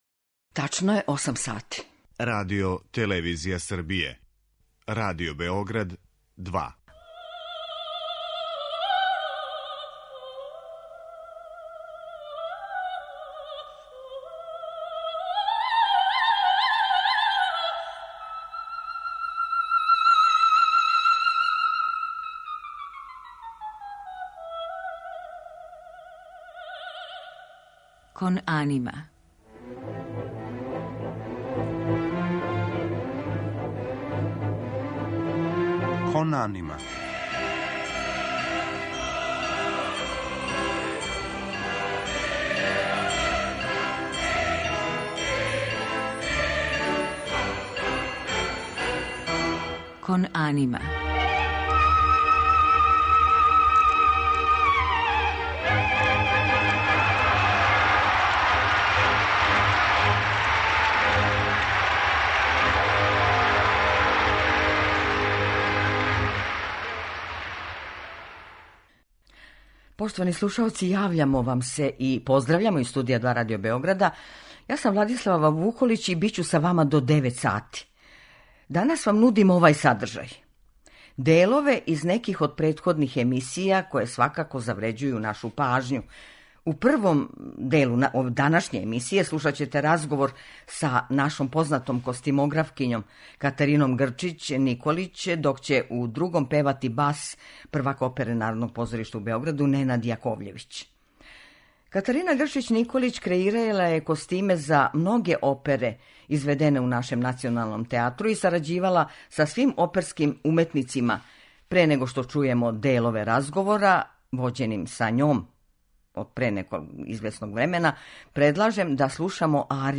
Кон анима је посвећена опери. Портрети уметника, занимљивости из света оперe, анегдоте и оперске звезде, освежавају овај својеврсни радио водич кроз захтевни свет музичке сцене.